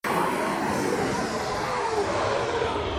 wight_dies.ogg